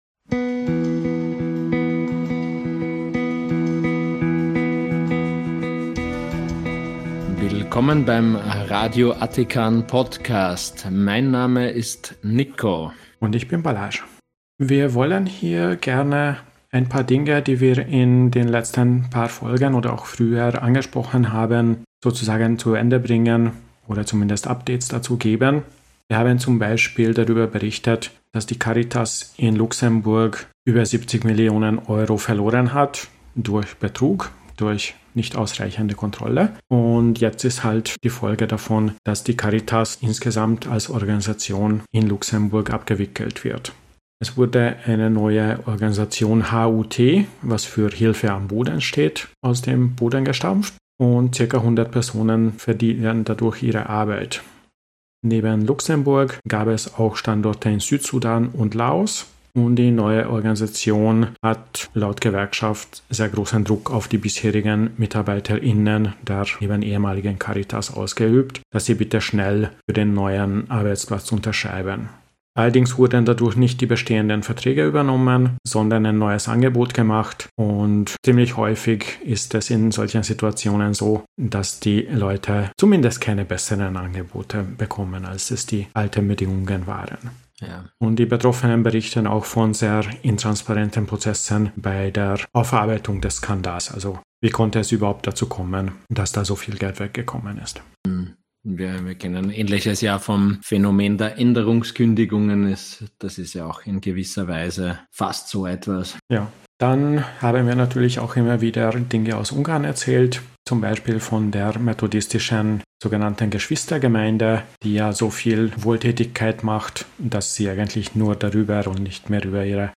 Der kritische Podcast aus Österreich, mit Nachrichten, Themen und Interviews aus der säkularen und skeptischen Szene